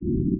The original sound is a low frequency noise (cut-frequency at 780Hz).
(Virtual source at -90°)
Max rE decoding LF noise o1 max rE